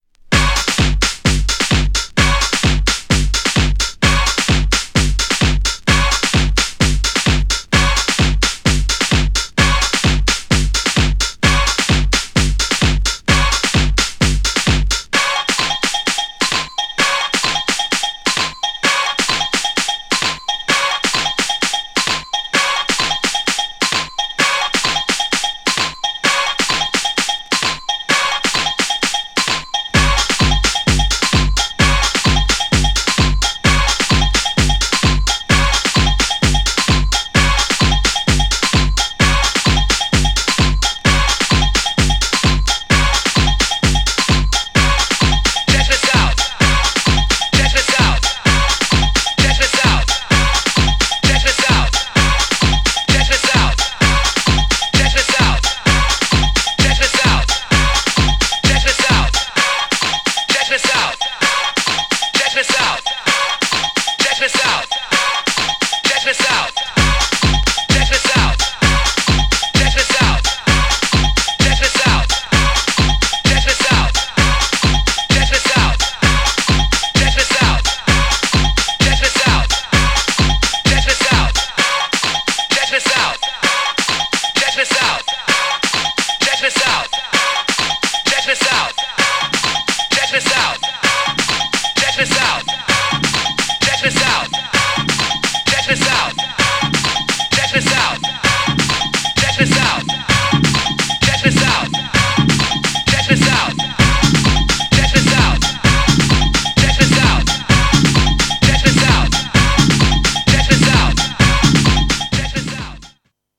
アッパーなトライバルTRACKのA面と、
GENRE House
BPM 131〜135BPM
じわじわ盛り上がる # ドラマティック # 空間的